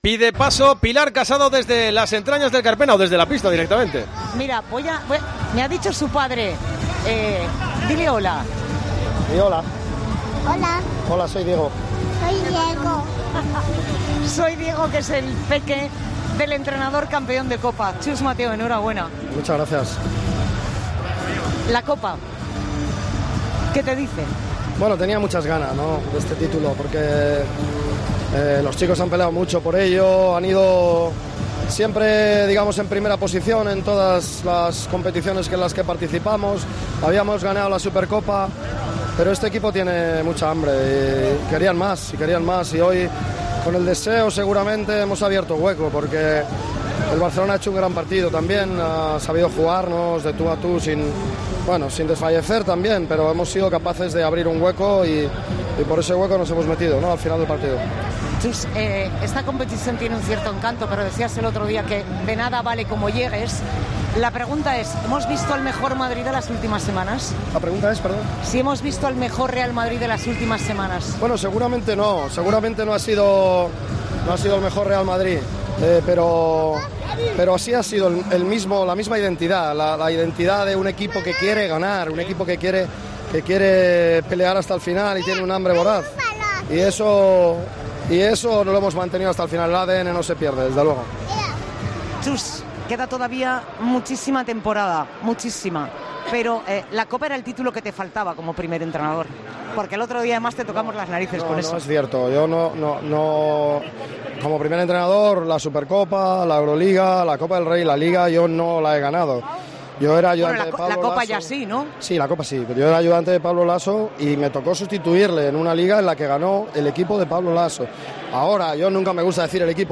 habla con el entrenador del Real Madrid tras proclamarse campeón de la Copa del Rey de baloncesto.